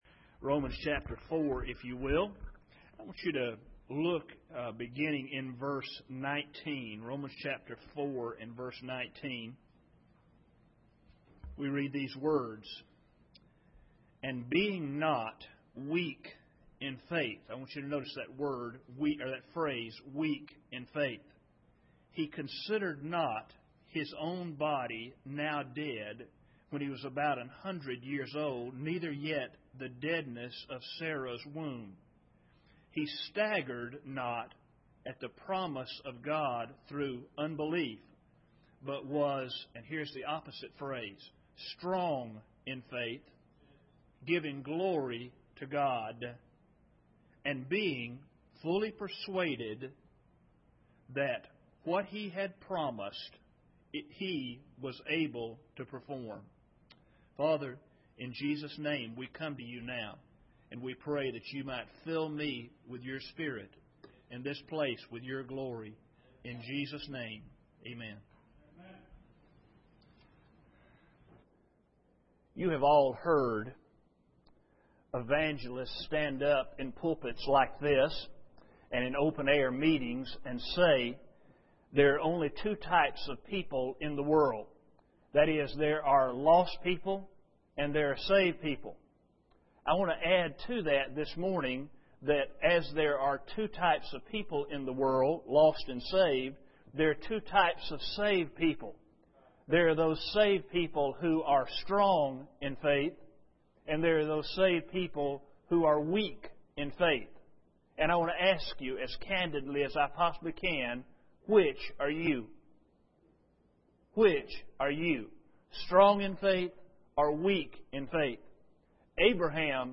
Romans 4:19-21 Service Type: Sunday Morning This is a great revival message that our pastor